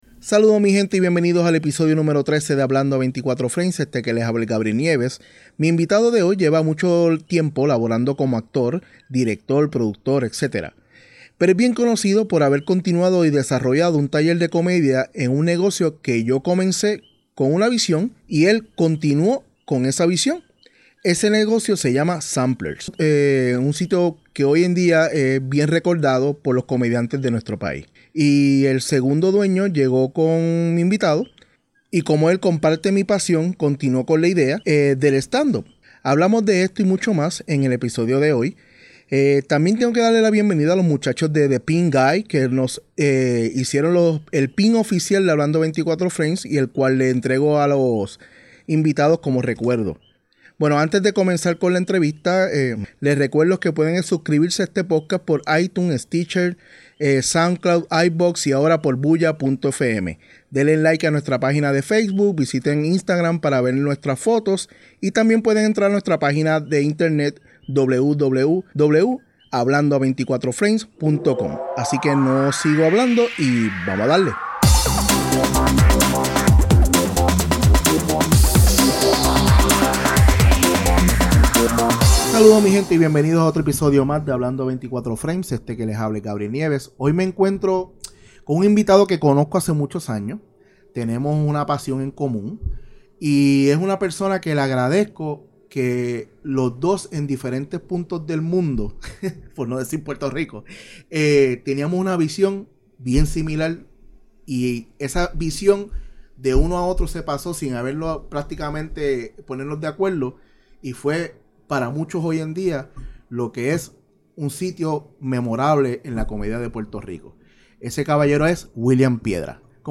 Hablamos de eso y un montón de cosas más. Espero que se disfruten esta conversación